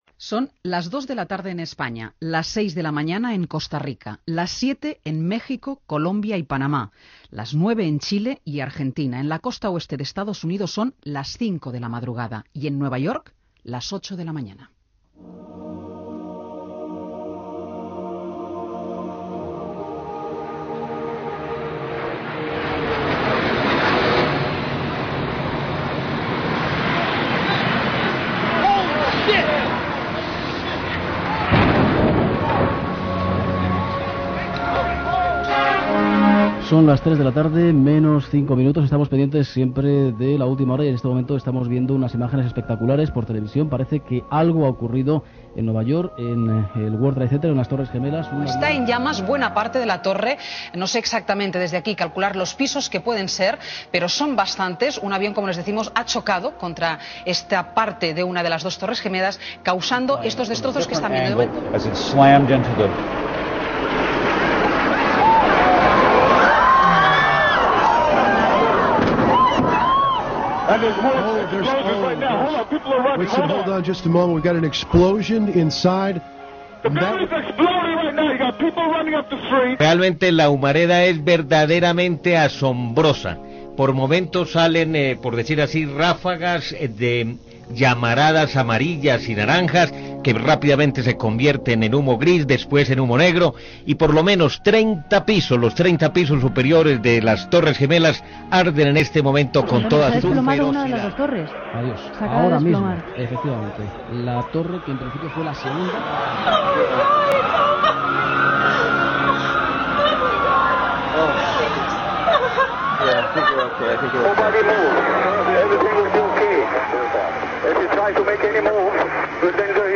Hora, record sonor a les informacions donades l'11 de setembre de 2001, salutació als oïdors de totes les emissores de Prisa Ràdio connectades, connexió amb Nova York, atac a una base de l'OTAN a l'Afganistan.
Informatiu